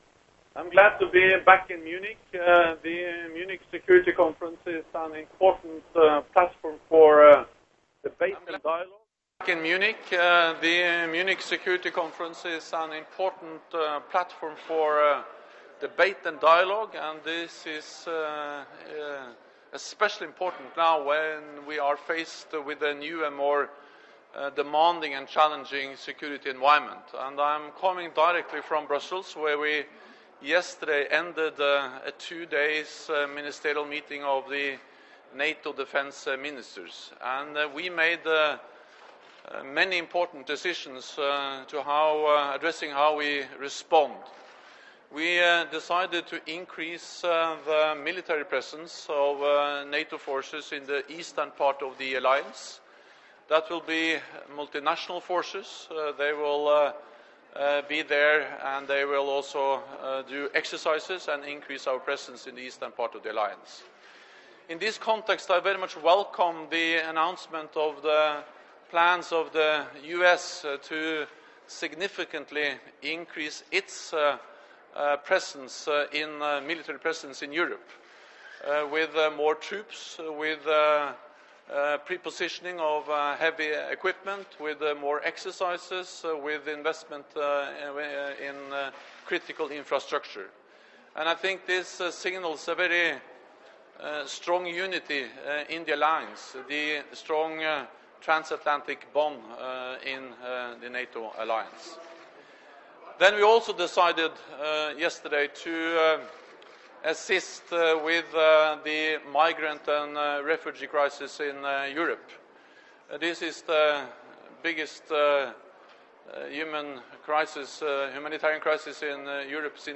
Speech by NATO Secretary General Jens Stoltenberg at the Munich Security Conference 13 Feb. 2016 | download mp3 Doorstep by NATO Secretary General Jens Stoltenberg upon arrival at the Security Conference in Munich 12 Feb. 2016 | download mp3